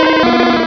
pokeemerald / sound / direct_sound_samples / cries / charmander.aif